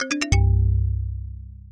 Звуки выигрыша
Звуковое сопровождение прохождения уровня